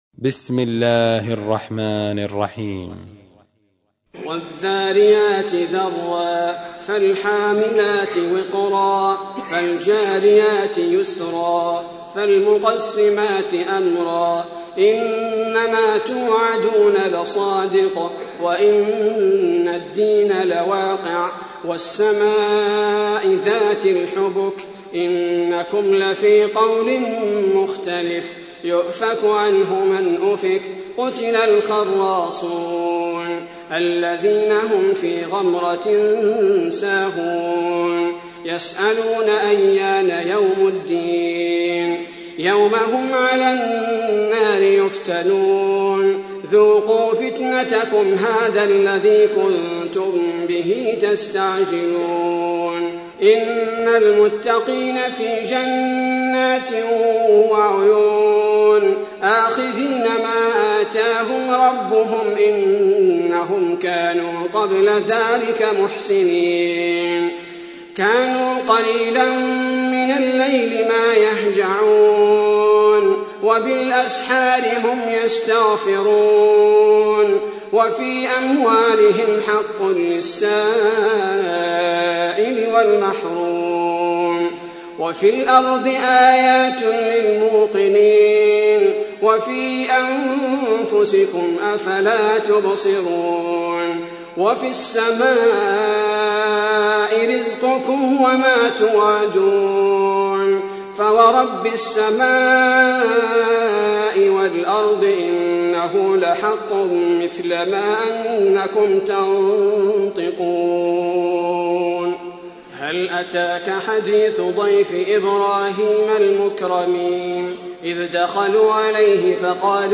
51. سورة الذاريات / القارئ